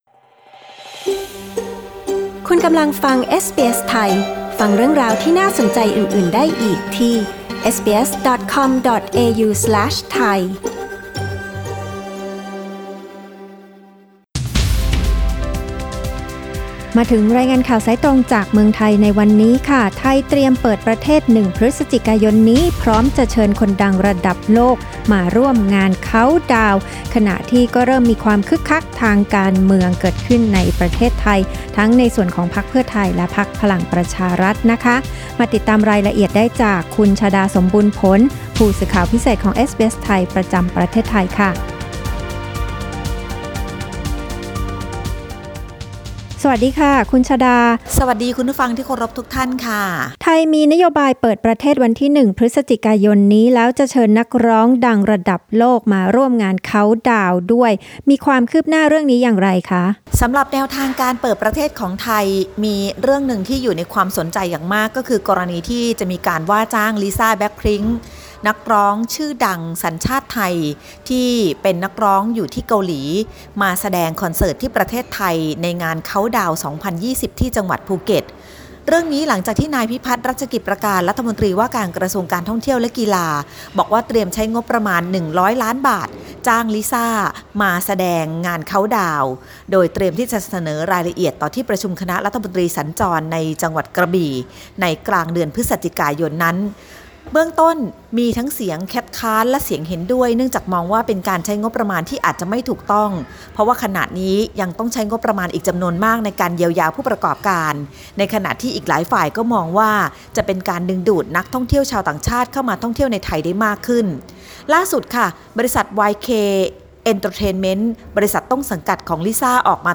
ฟังรายงานข่าว